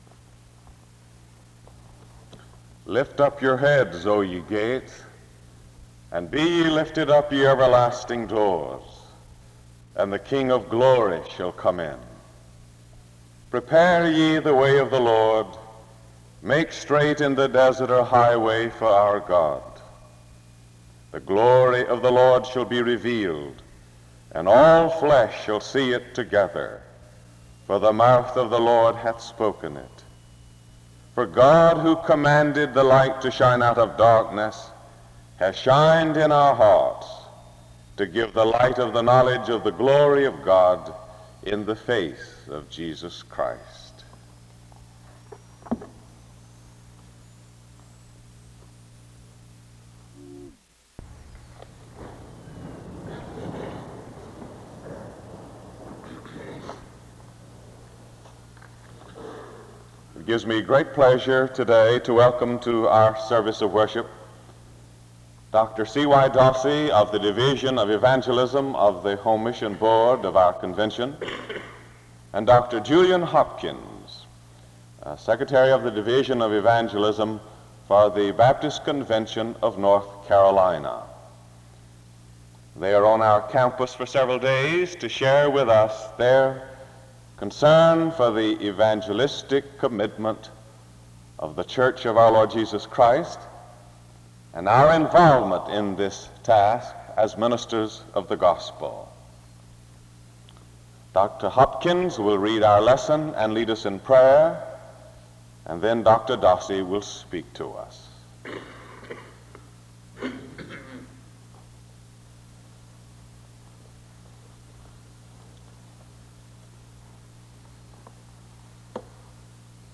Preaching
Location Wake Forest (N.C.)